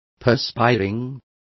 Complete with pronunciation of the translation of perspiring.